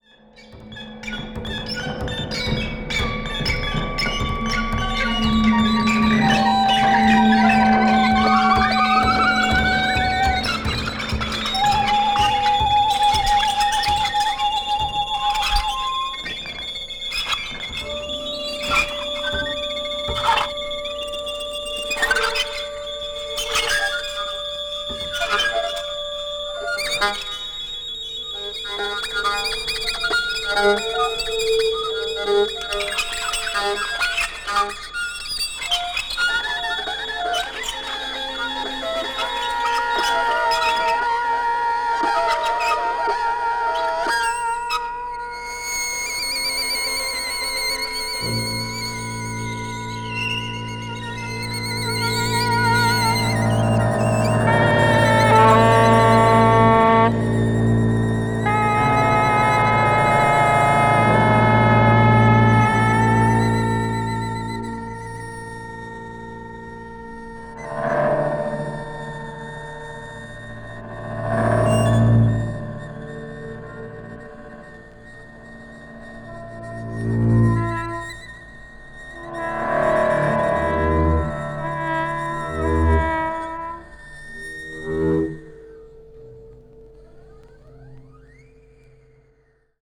20th century   contemporary   electronic   experimental